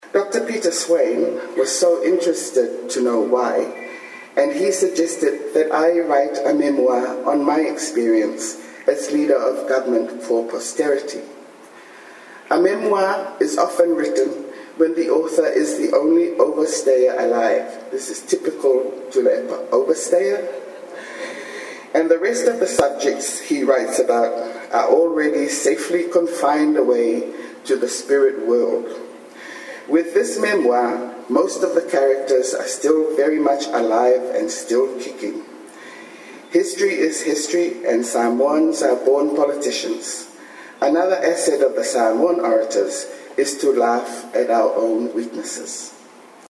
Those were the final words by Fofo Sunia to end his keynote address at the launch of the biography of Samoa’s Prime Minister Tuilaepa Sailele Malielegoi at the Lee Auditorium earlier today.
Fiame-reads-speech.mp3